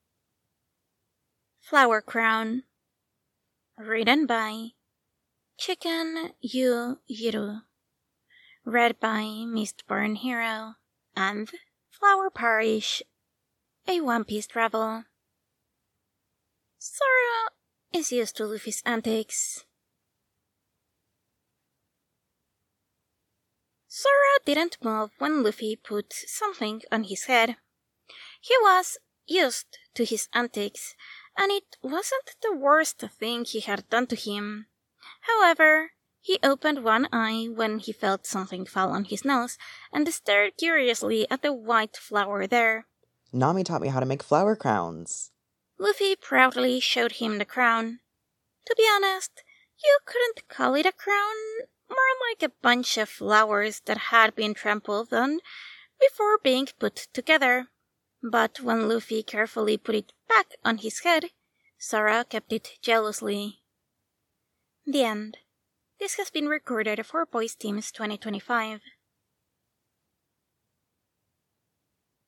collaboration|ensemble